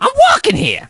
crow_get_hit_03.ogg